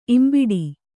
♪ imbiḍi